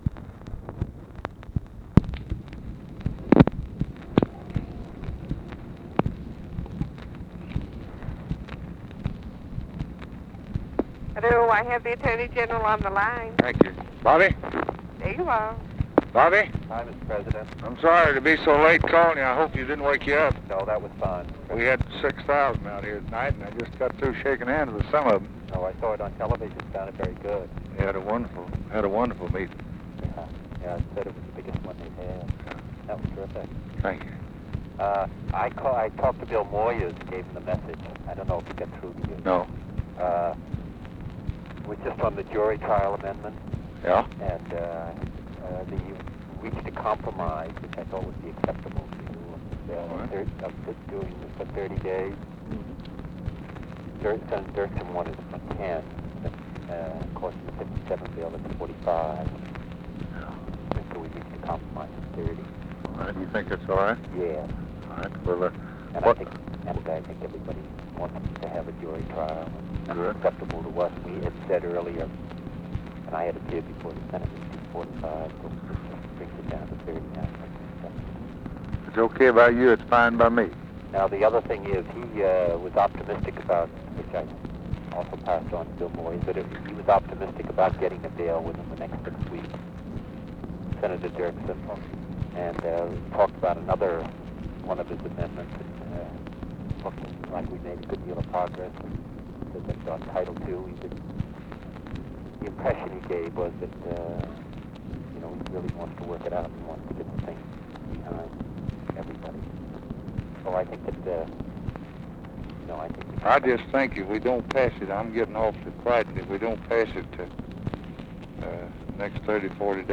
Conversation with ROBERT KENNEDY, April 23, 1964
Secret White House Tapes